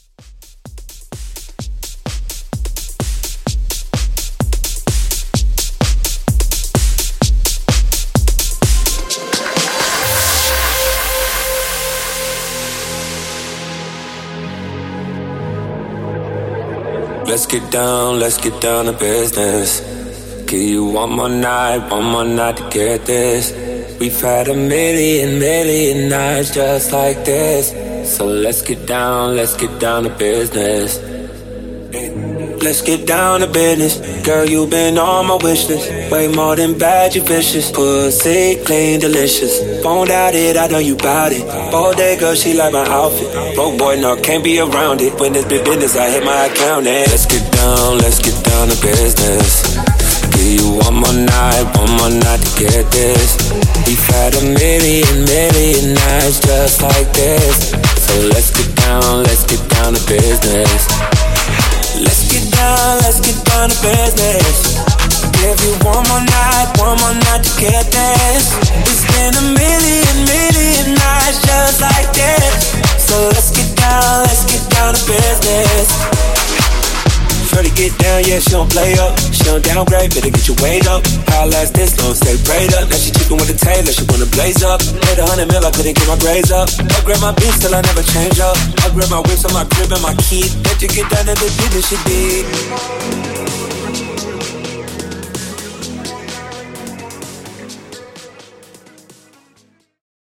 Extended House